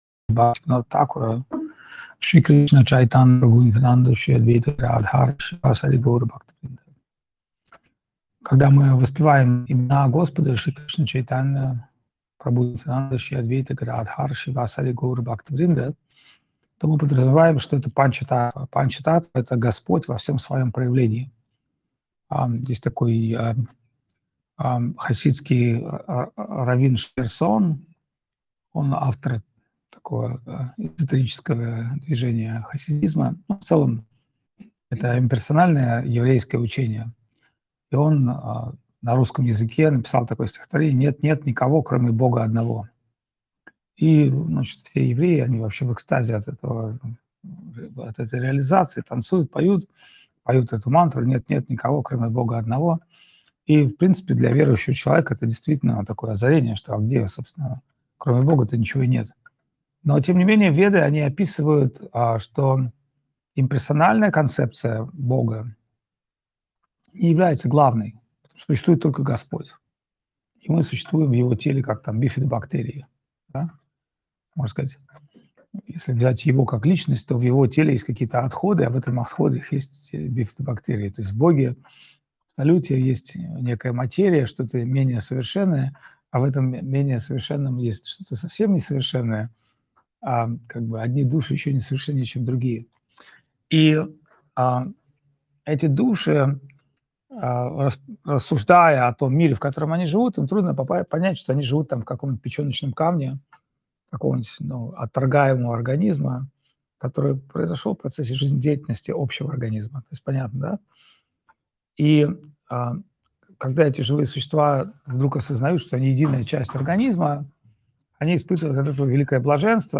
Чиангмай, Таиланд
Лекции полностью